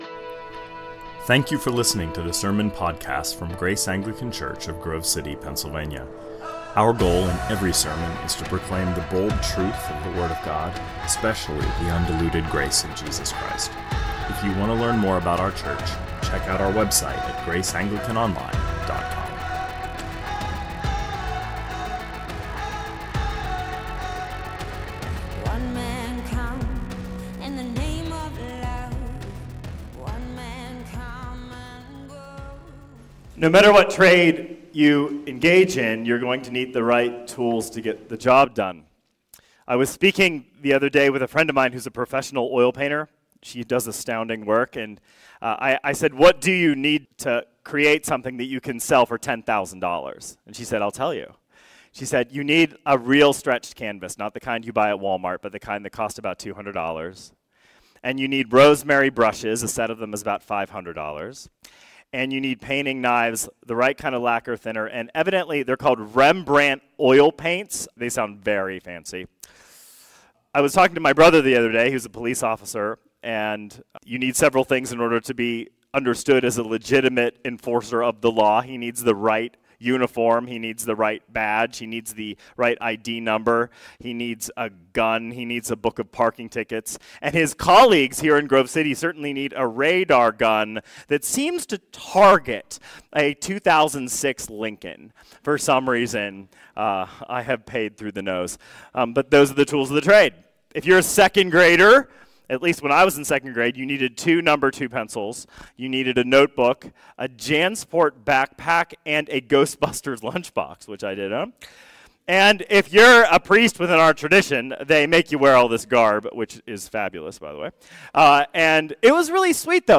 2024 Sermons John's Great Commission.